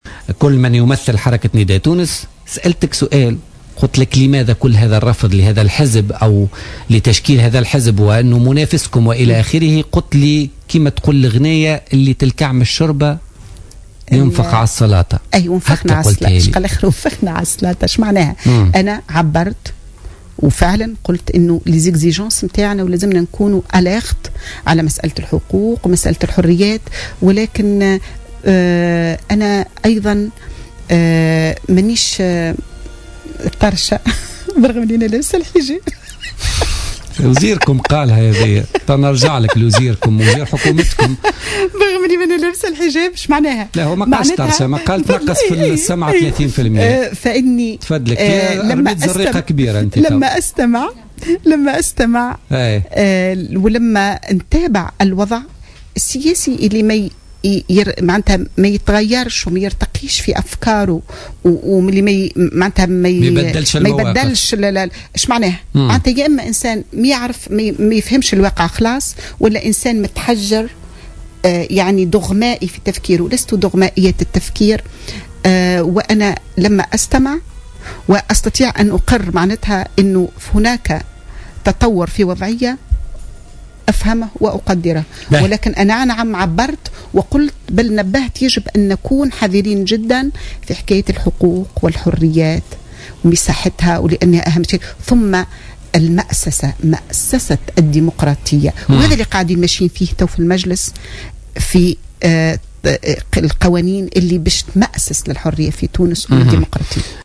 علقت النائب بمجلس نواب الشعب والقيادية في حركة النهضة محرزية العبيدي ضيفة برنامج بوليتكا